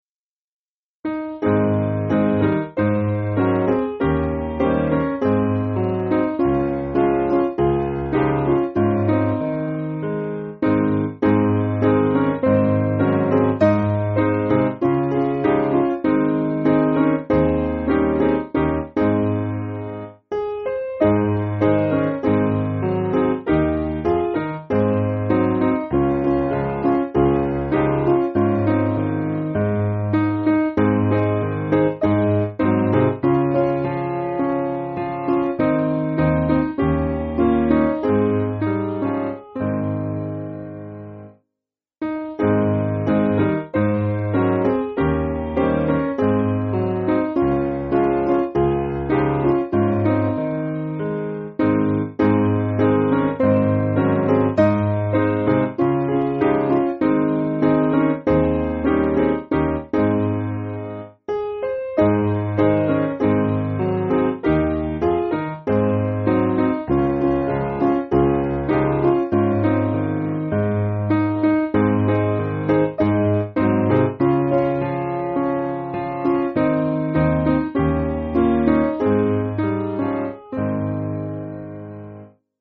Simple Piano
(CM)   4/Ab